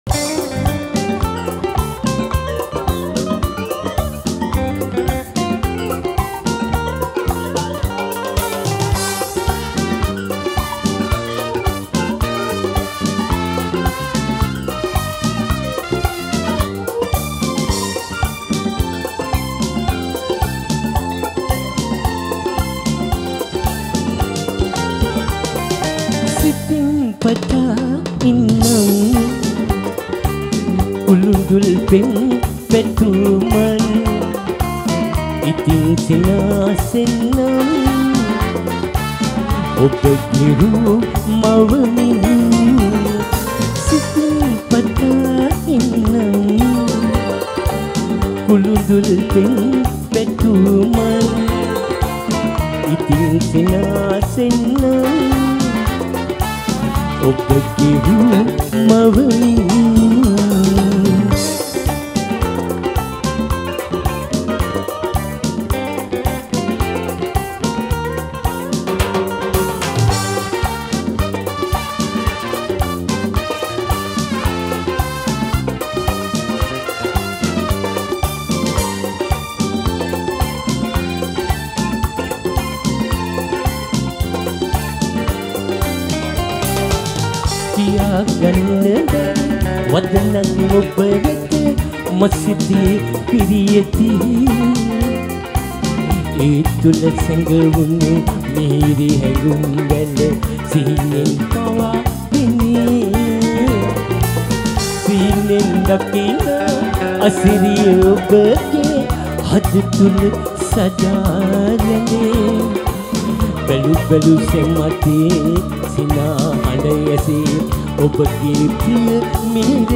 Releted Files Of Sinhala Live Show Single Songs